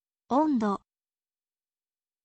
ondo